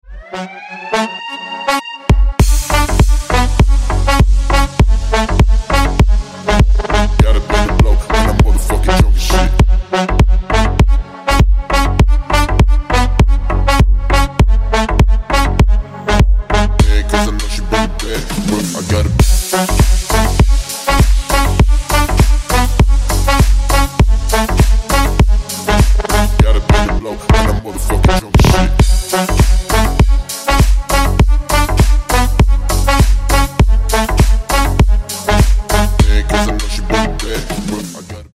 Громкие Рингтоны С Басами
Танцевальные Рингтоны